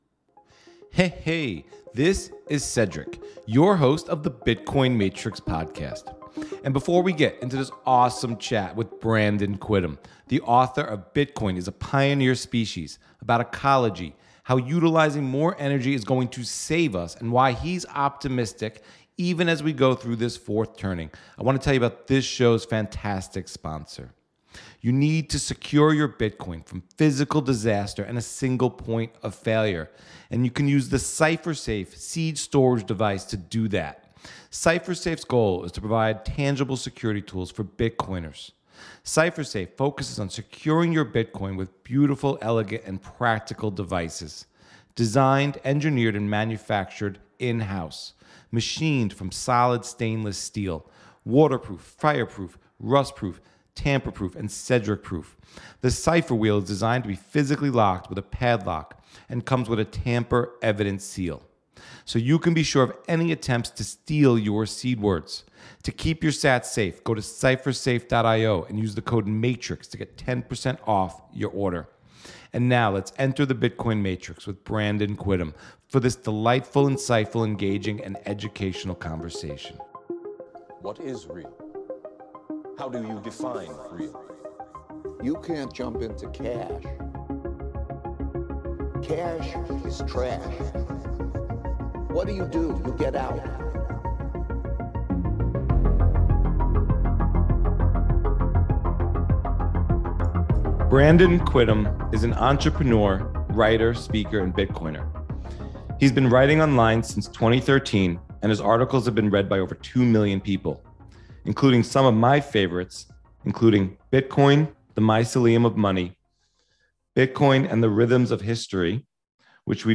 a wide ranging conversation about Bitcoin as a pioneer species, ecology